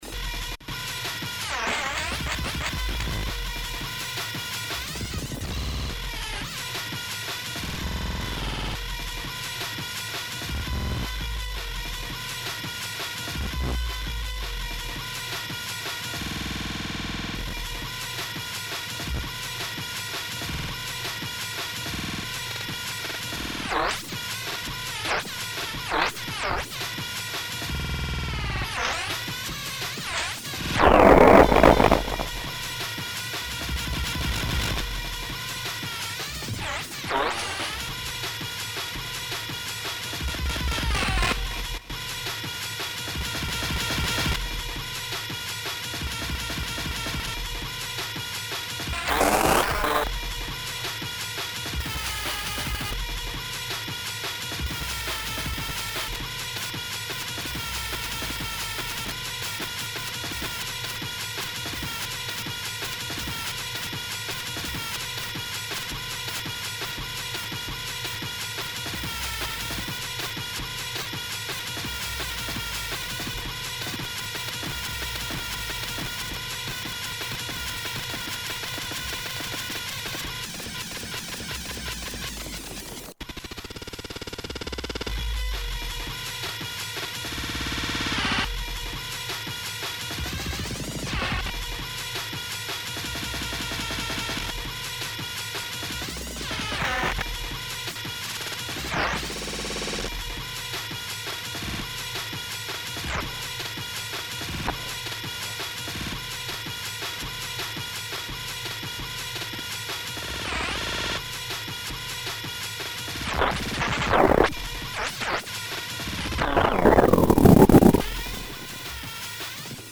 some of these are nearly 20mg - please watch your volumes there are some very loud sections in these. the samples are just me cutting crude loops of mine and other peoples work, computer - teaspoon - mixer - computer, nothing else. at a few points i plug in the attenuated audio output of a summed pair of sine VCO’s set to low frequencies into the CV input to simulate the audio outputs of a standard sound card, no other outside control is used. i try to cover as much range as i can without stopping on anything for too long so you will have to use your imagination to pause things a bit, there is a lot to cover. most tracks have the right side clean so you can compare the two. most of the time is spent with the SZ, FDBK, LNGTH and THRSH controls, the push button is only used a few times, tilt disabled.